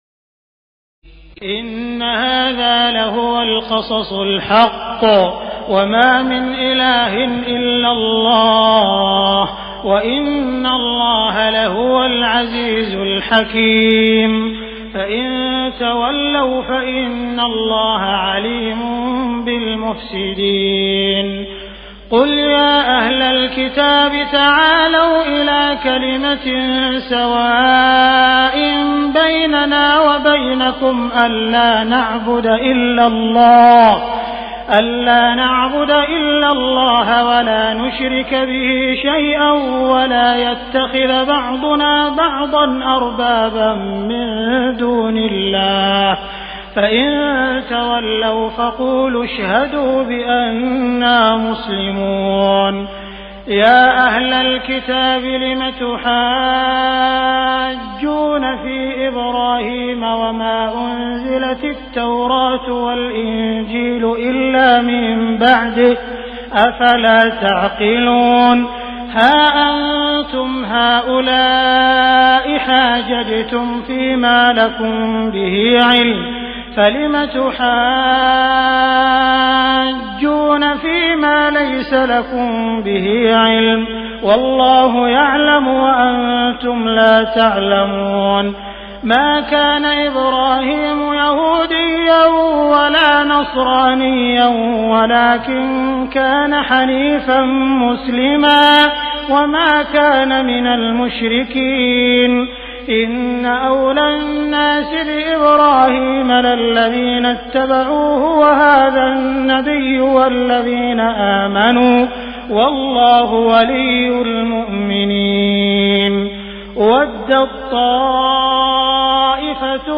تراويح الليلة الرابعة رمضان 1418هـ من سورة آل عمران (62-151) Taraweeh 4st night Ramadan 1418H from Surah Aal-i-Imraan > تراويح الحرم المكي عام 1418 🕋 > التراويح - تلاوات الحرمين